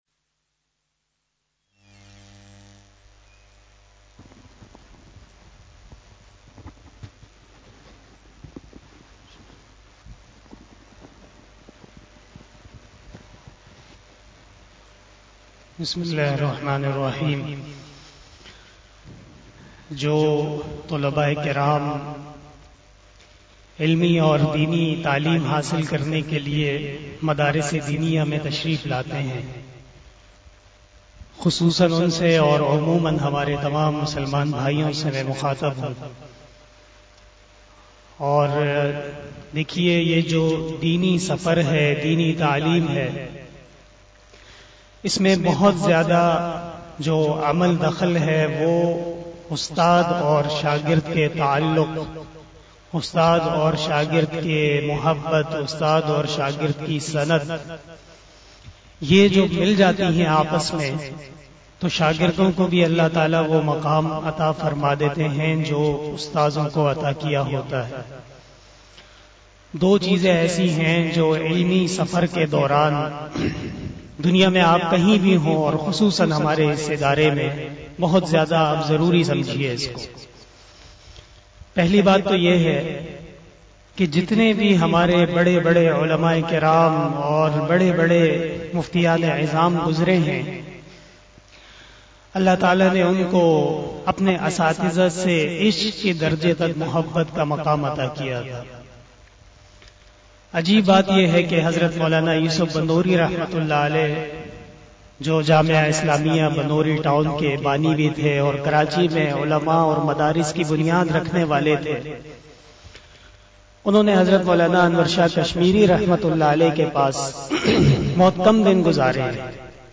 079 After magrib Namaz Bayan 09 November 2021 (03 Rabi us Sani 1443HJ) Tuesday
After Namaz Bayan